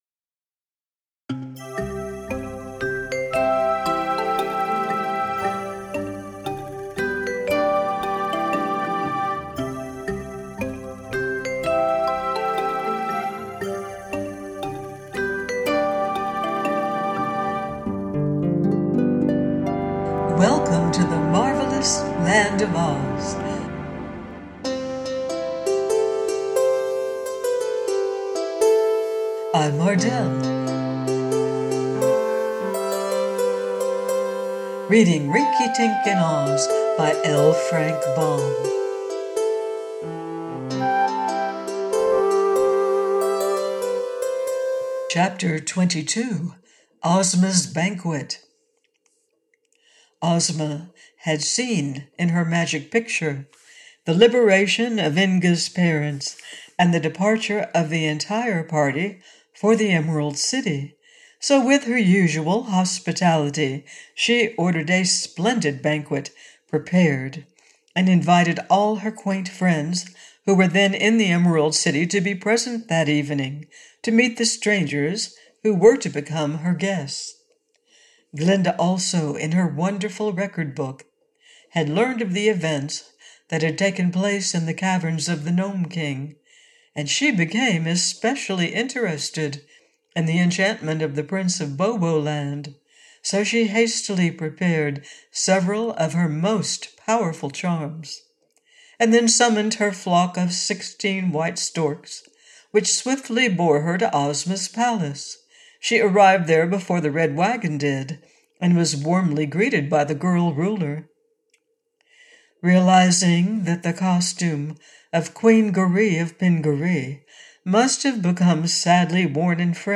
Rinkitink In Oz – by Frank L. Baum - AUDIOBOOK